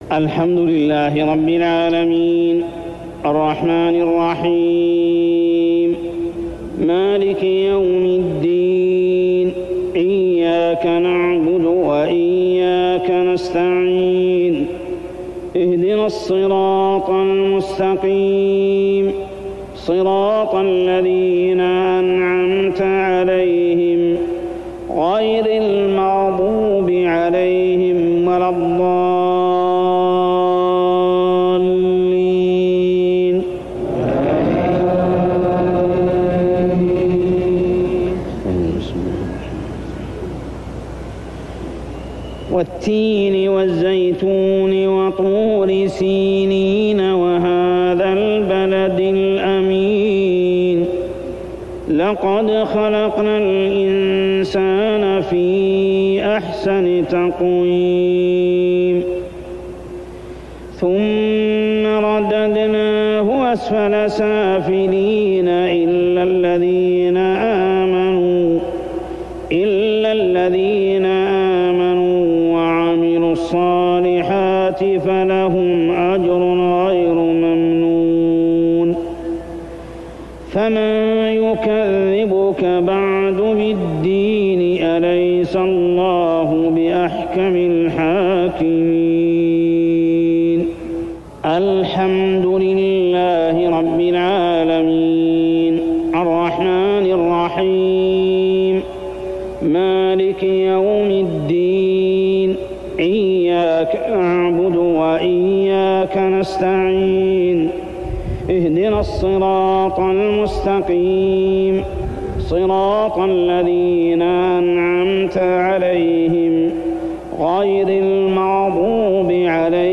عشائيات عام 1404هـ سورتي التين و القدر كاملة | Isha prayer surah at-Tin and al-qadr > 1404 🕋 > الفروض - تلاوات الحرمين